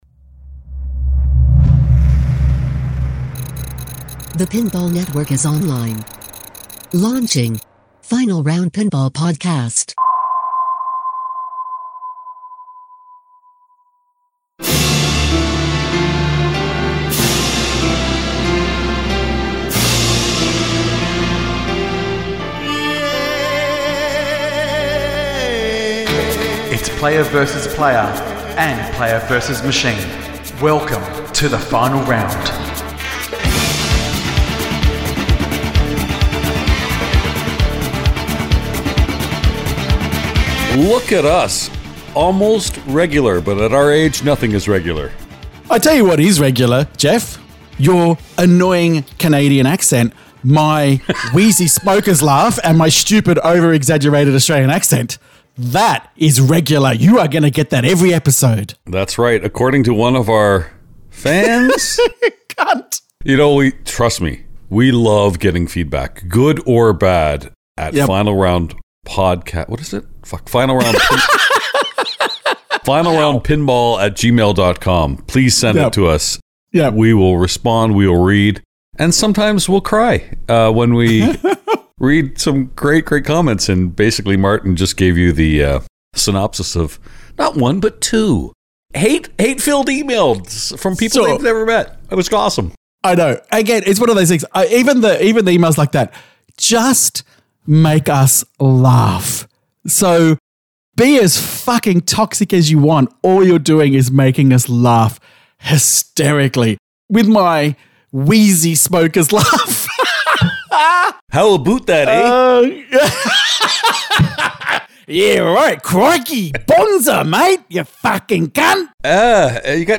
Exaggerated accents? Yeah we’ve got them. Nasty smokers coughs? Oh don’t worry we are ALL ABOUT smokers coughs!!